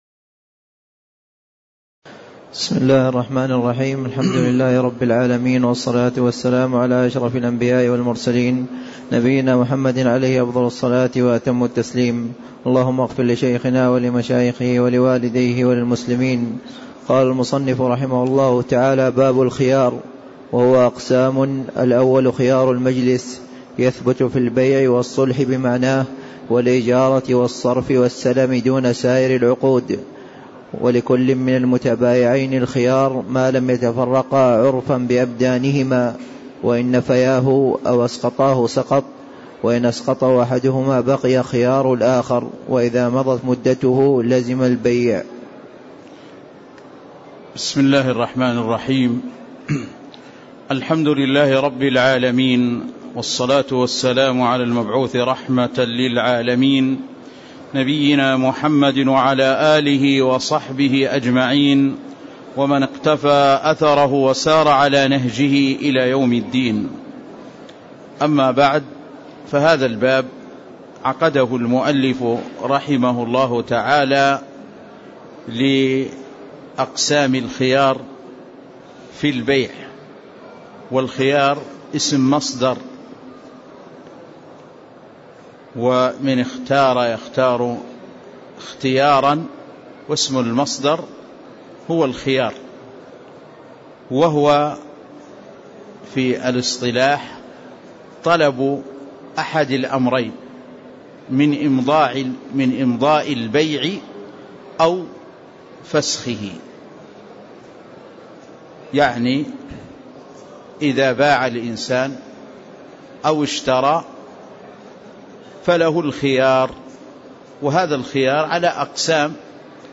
تاريخ النشر ٢ رجب ١٤٣٦ هـ المكان: المسجد النبوي الشيخ